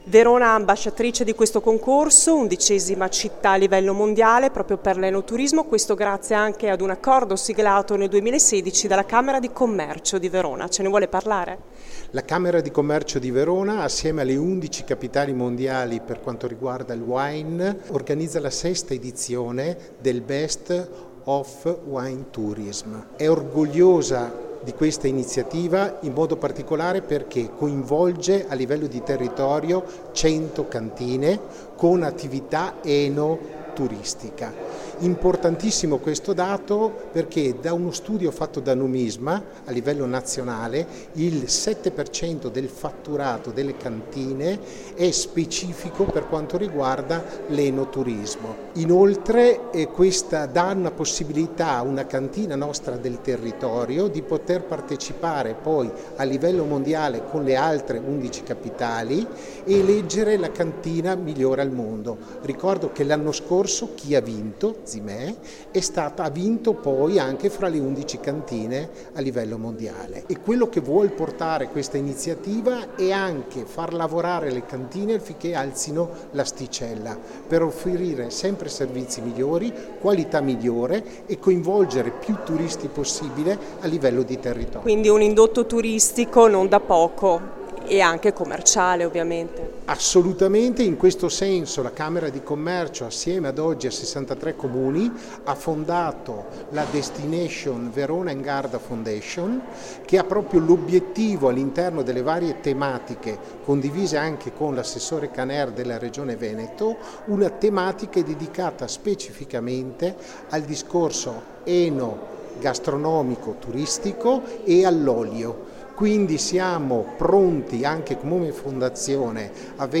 Al microfono della nostra corrispondente